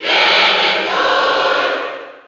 Ganondorf_Cheer_NTSC_SSB4.ogg.mp3